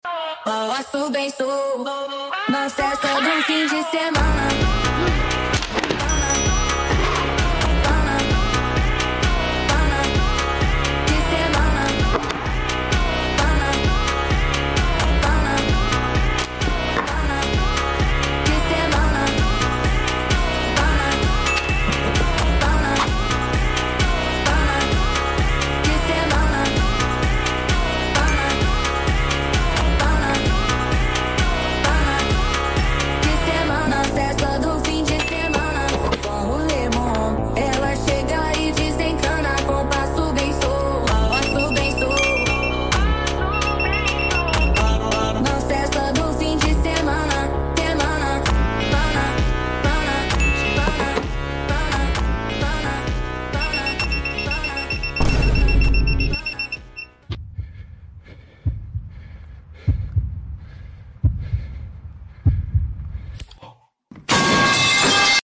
Lighting Sound Effects Free Download